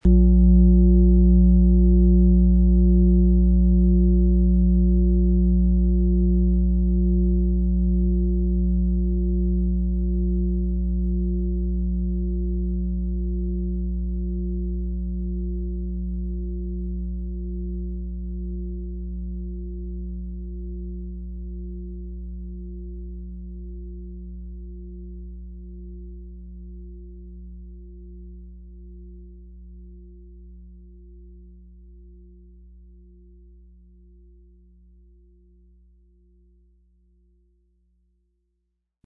XXXL Fußreflexzonenschale mit Planetenton Mond ca.10,26 kg, Ø 45,8 cm, mit Klöppel, ca.bis Schuhgröße 43
Ihre sanften, tragenden Schwingungen unterstützen dich dabei, dich zu öffnen, loszulassen und innere Sicherheit zu finden.
• Sanfte Schwingung: Berührend und ausgleichend – ideal für emotionale Tiefe.
MaterialBronze